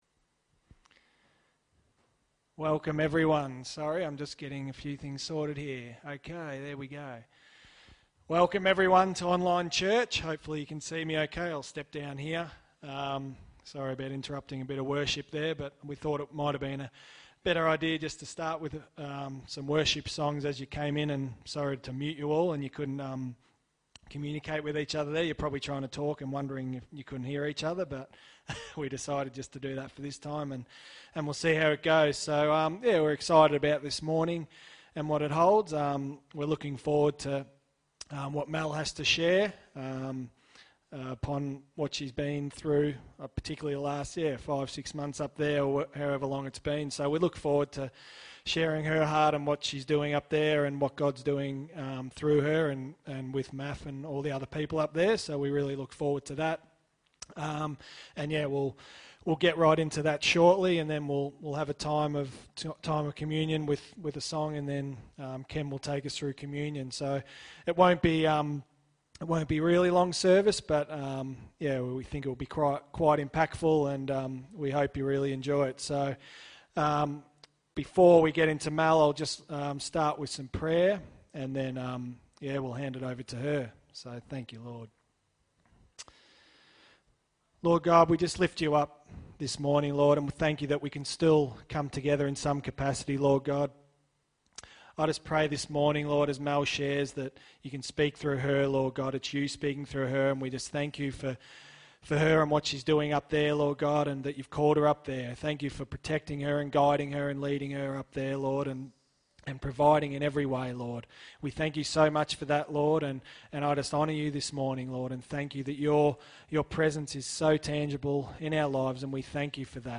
Sunday Service 23/8/2020 (audio only)